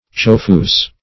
Search Result for " chauffeuse" : Wordnet 3.0 NOUN (1) 1. a woman chauffeur ; The Collaborative International Dictionary of English v.0.48: Chauffeuse \Chauf`feuse"\, n. [F., fem. of chauffeur.]